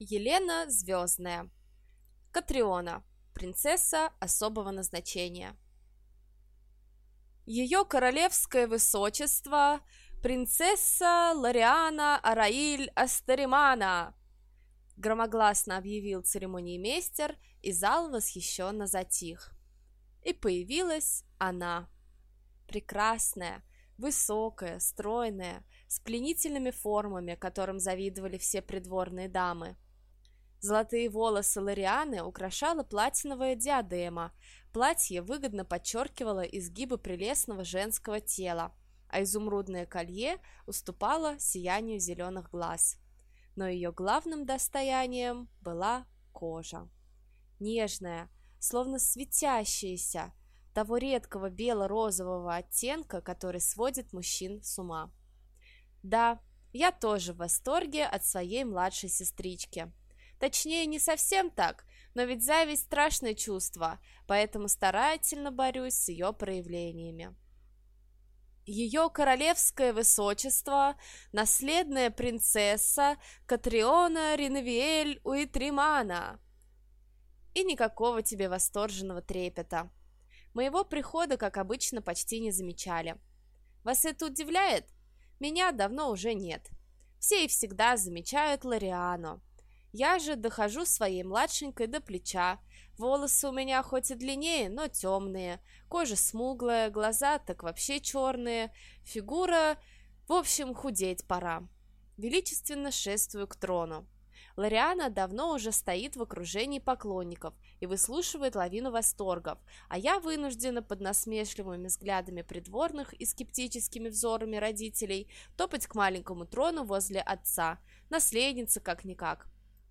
Аудиокнига Принцесса особого назначения - купить, скачать и слушать онлайн | КнигоПоиск